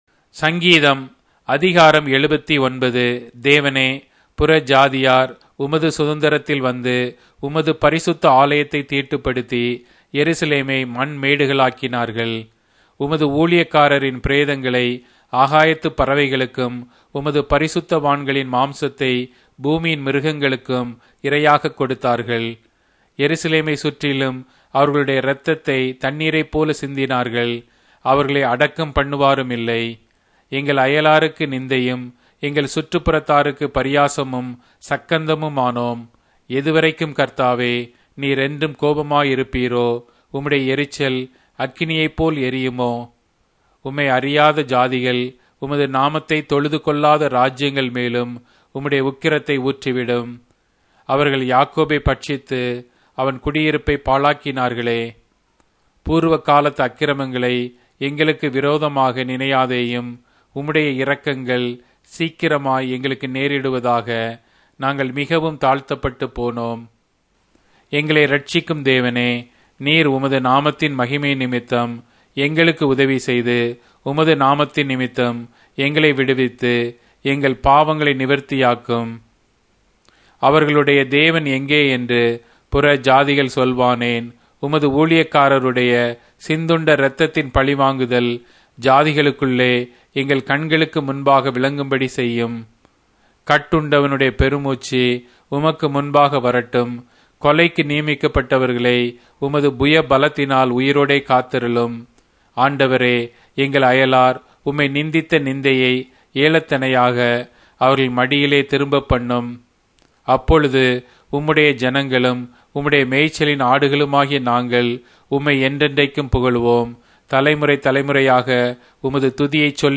Tamil Audio Bible - Psalms 33 in Asv bible version